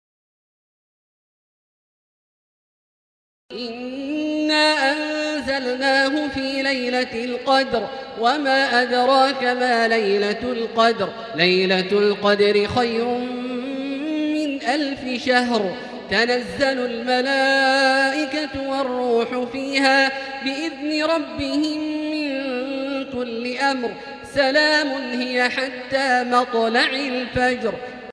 المكان: المسجد الحرام الشيخ: فضيلة الشيخ عبدالله الجهني فضيلة الشيخ عبدالله الجهني القدر